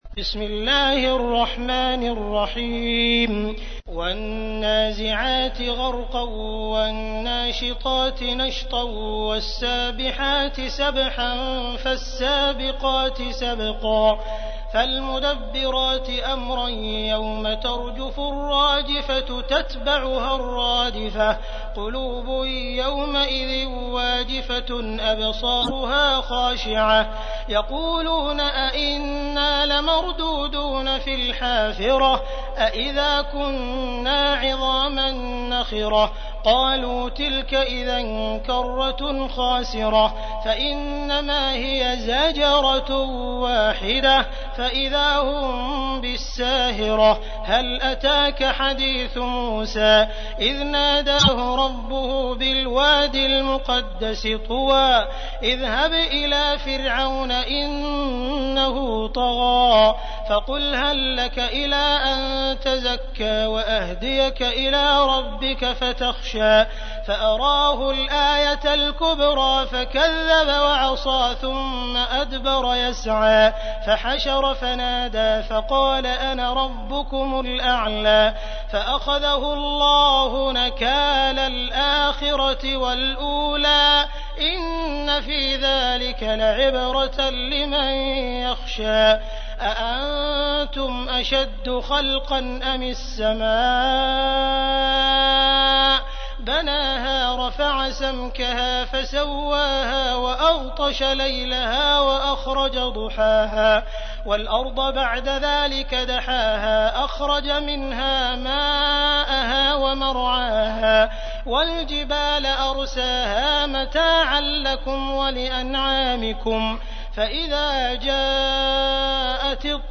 تحميل : 79. سورة النازعات / القارئ عبد الرحمن السديس / القرآن الكريم / موقع يا حسين